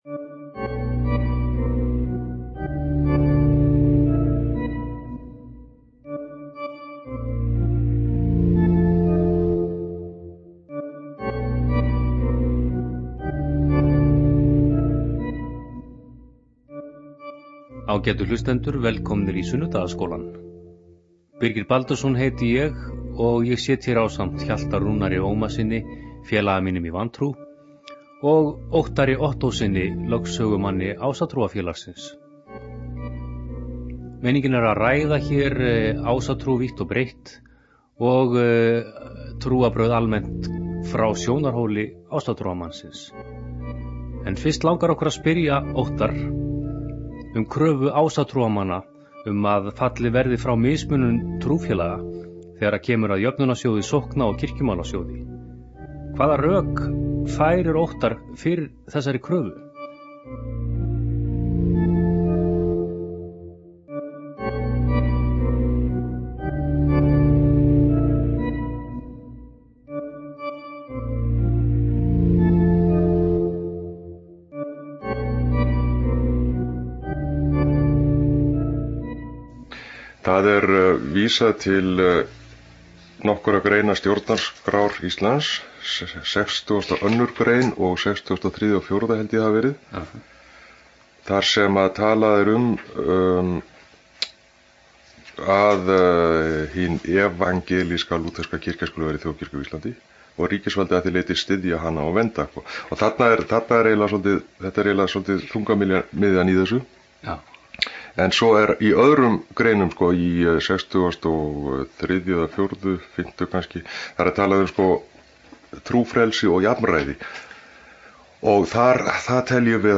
�etta er fyrri hluti vi�talsins, en restin fer � lofti� eftir viku.